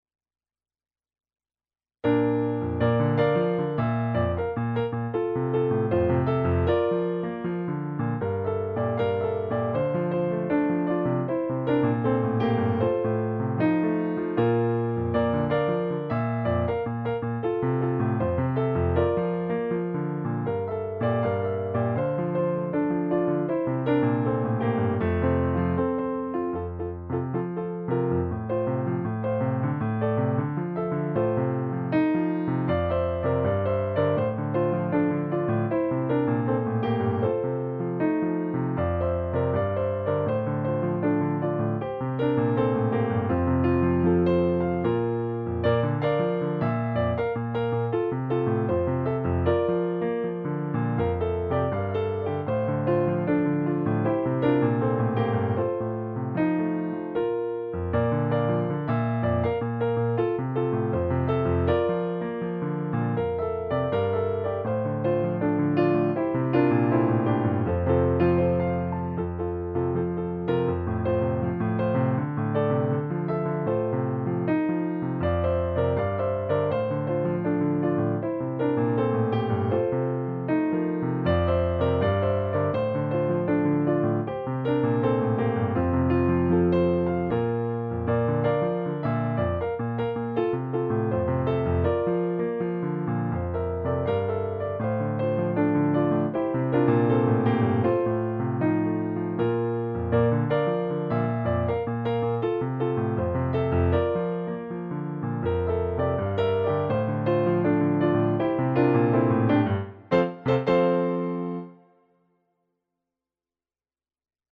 Segue nesse novo projeto, a gravação audiovisual dos arranjo para as 17 músicas do Cordão, para canto coral e piano.
Piano s/ Clique